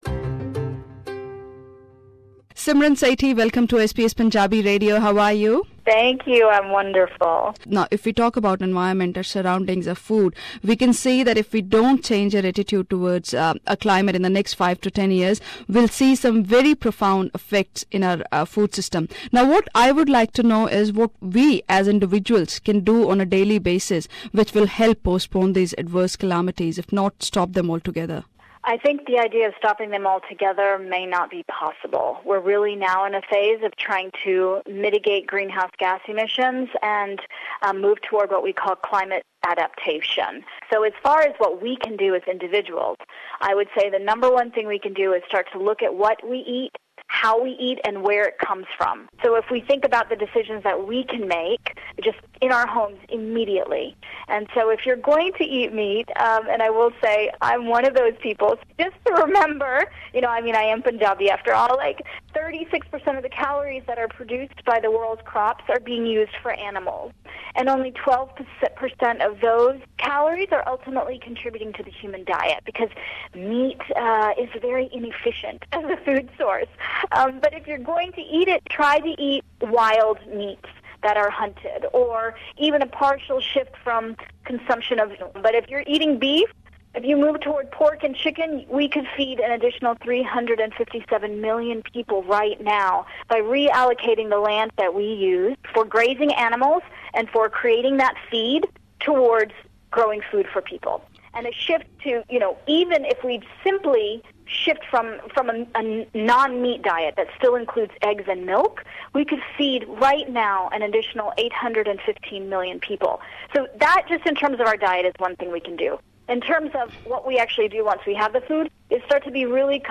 A conversation with Simran Sethi- the environmental hero
Listen to this interview with Simran Sethi to know her views about our environment, food system and how we as individuals can contribute to sustain our climate culture.